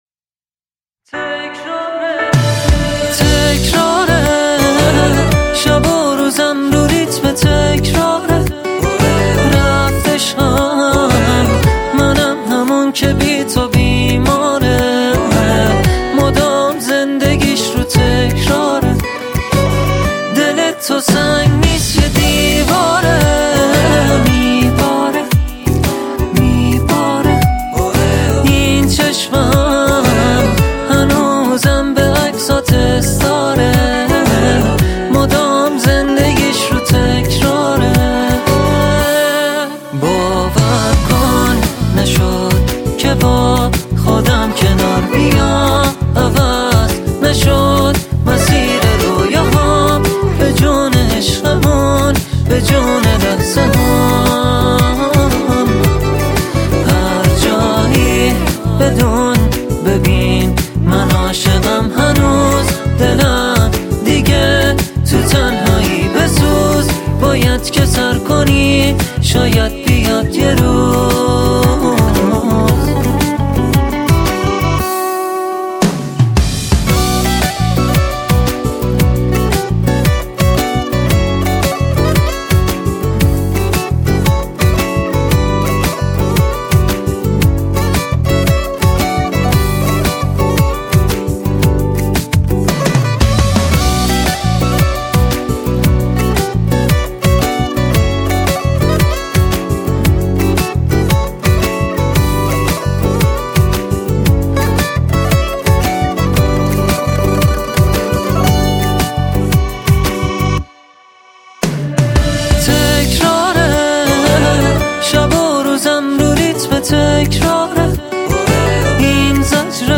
غمگین ، ملایم ، پاپ